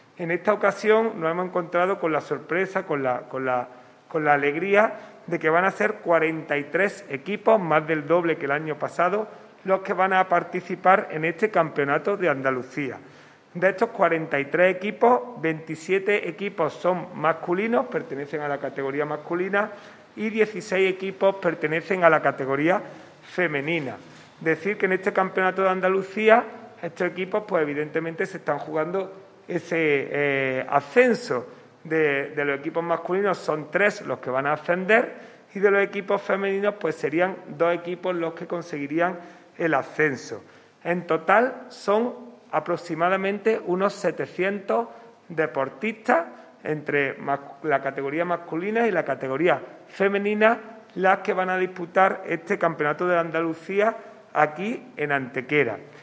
El teniente de alcalde delegado de Deportes, Juan Rosas
Cortes de voz